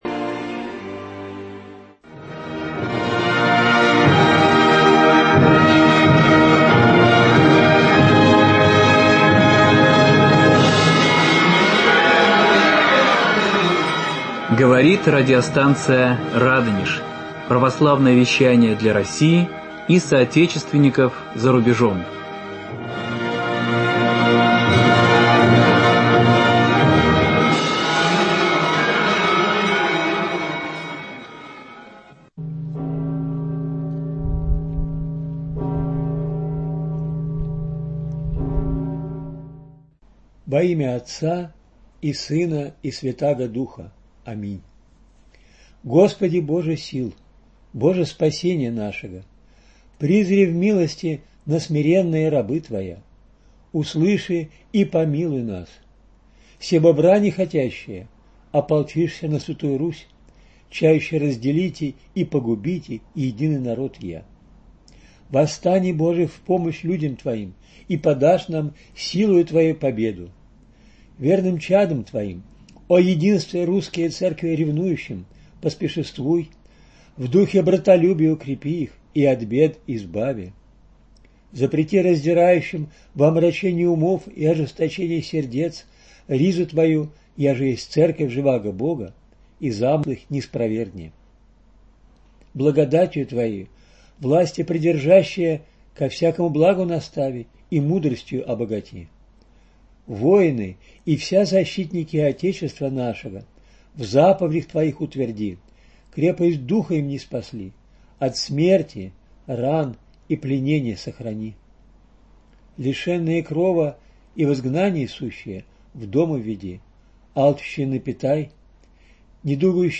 Авиация Первой Мировой войны. Премьера радиоцикла | Радонеж.Ру